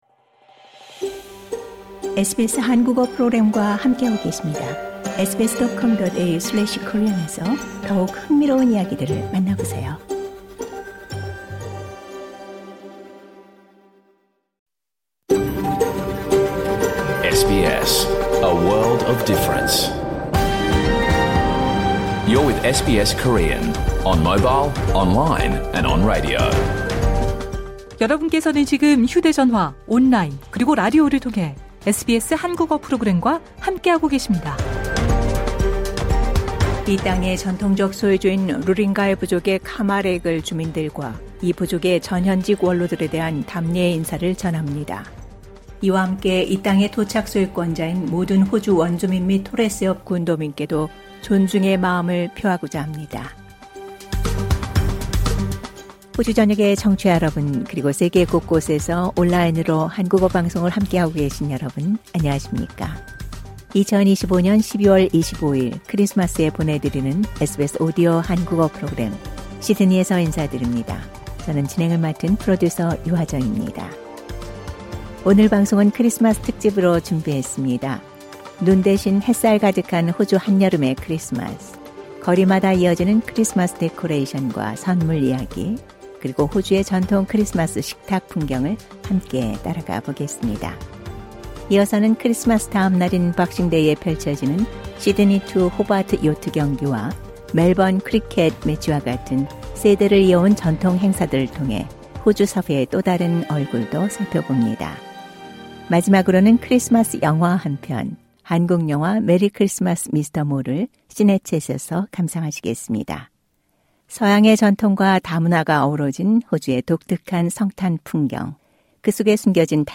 2025년 12월 25일 목요일에 방송된 SBS 한국어 프로그램 전체를 들으실 수 있습니다.